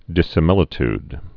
(dĭsə-mĭlĭ-td, -tyd)